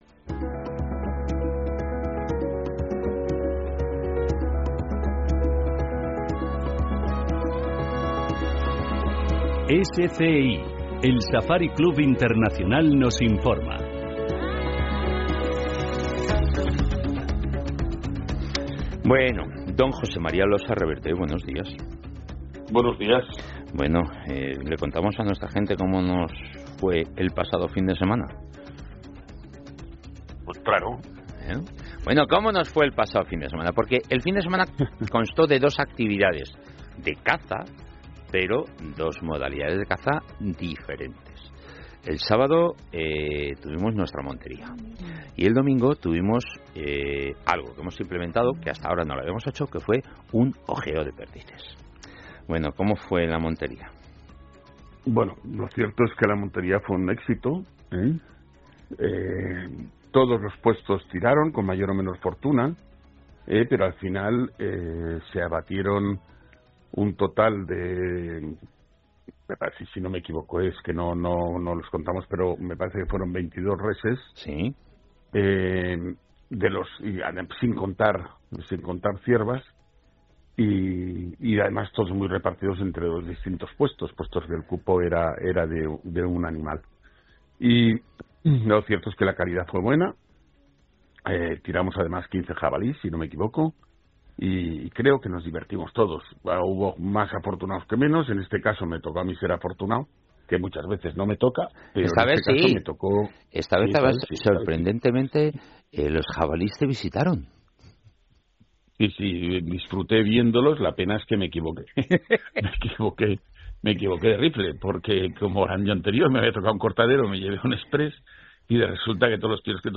Finalmente, se discute la controversia sobre la posible reintroducción del lince boreal en el Pirineo y la necesidad de basar tales decisiones en estudios científicos rigurosos. ESCUCHA LA ENTREVISTA >> PROGRAMA COMPLETO >>